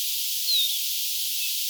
Tiidy-vihervarpunen lensi jälleen ylitse
Tällaisia sen äänet olivat.
tiidy-tunnistusääni
tuollainen_tiidy_vihervarpuslinnun_tunnistusaani.mp3